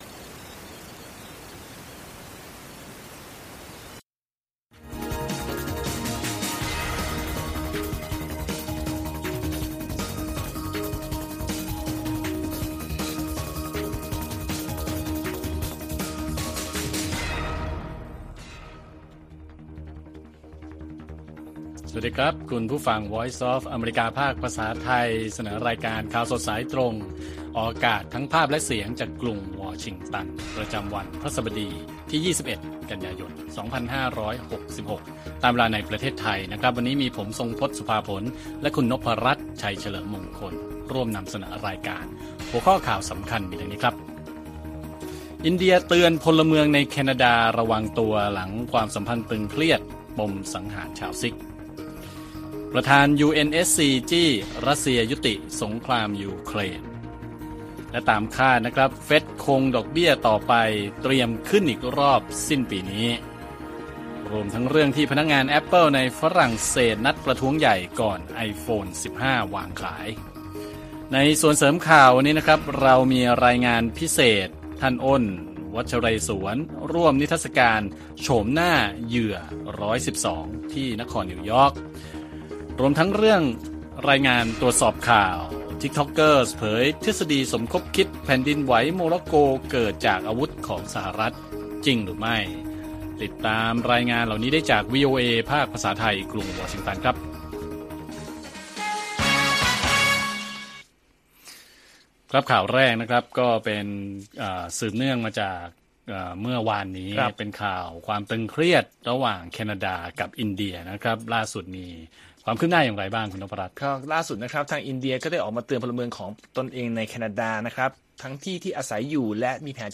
ข่าวสดสายตรงจากวีโอเอไทย วันพฤหัสบดี ที่ 21 ก.ย. 2566